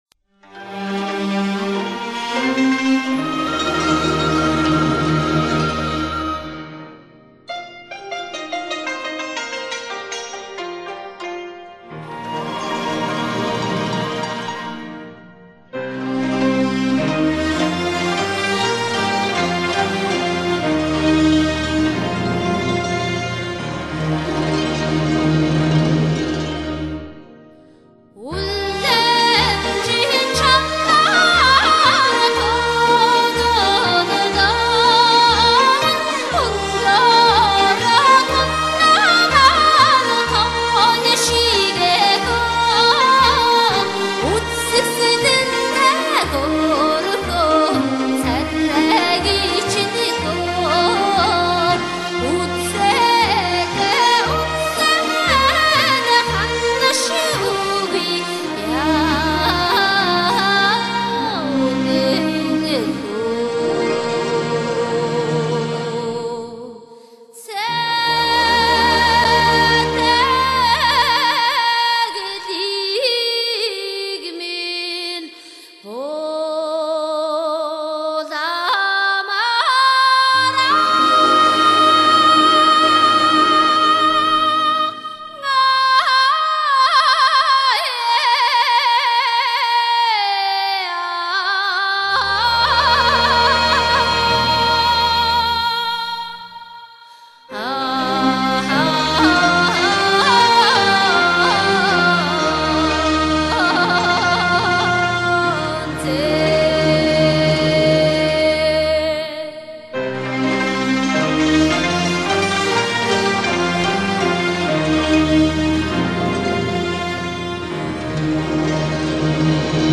蒙古原生态音乐之旅